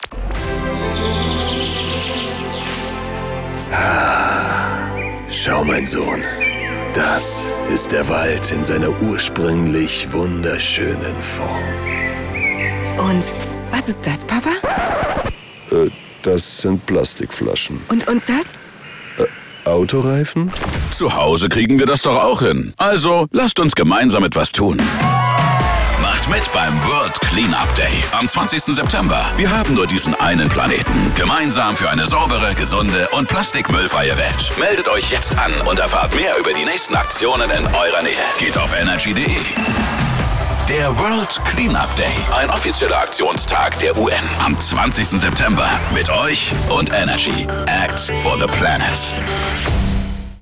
Gemeinsam für eine saubere Welt: NRJ macht Werbung für den World Cleanup Day